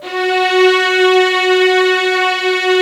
ZG3 STRS F#3.wav